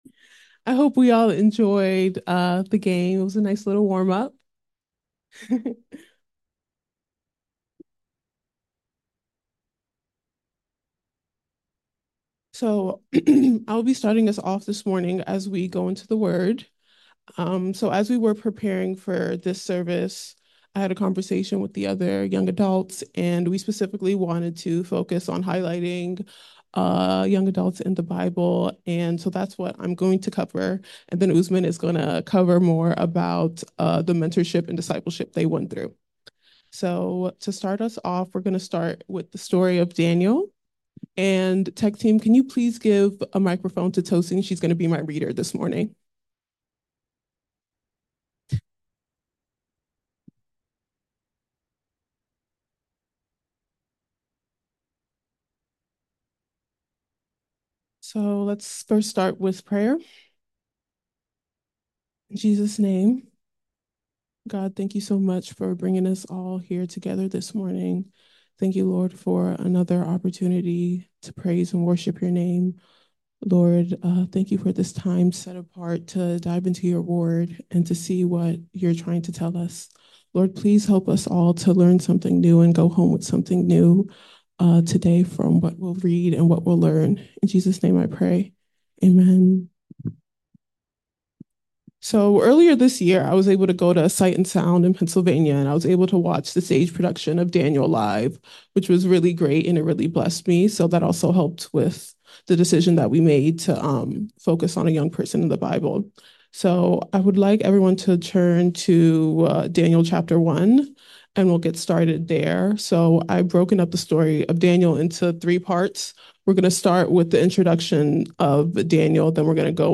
From Series: "Sermons"